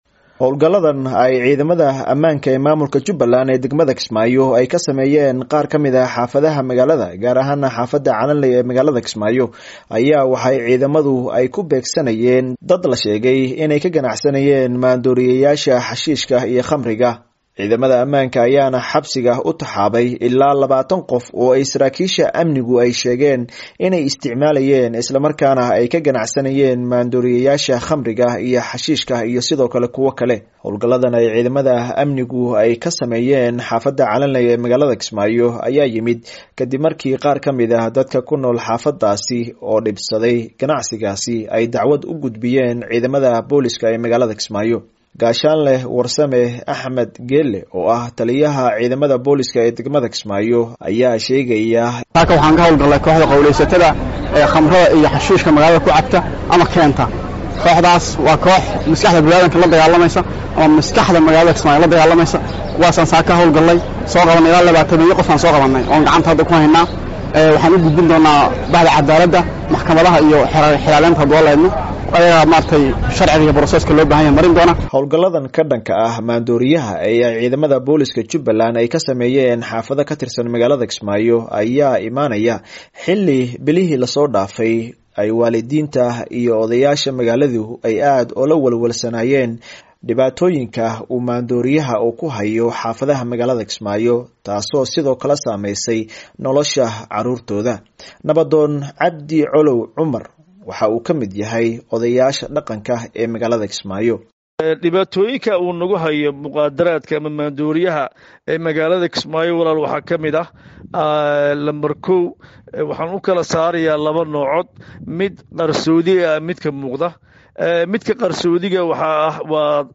warbixintan